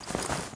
SFX_InvOpen_01.wav